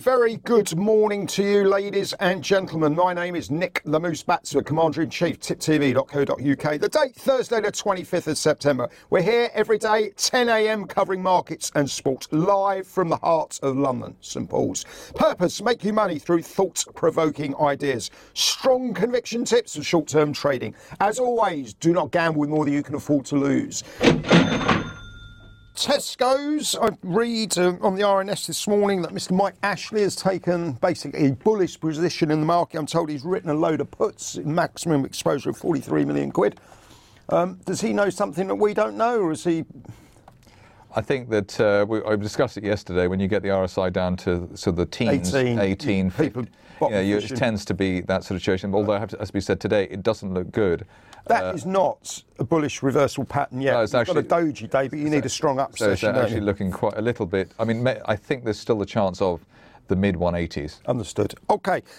Live Market Round Up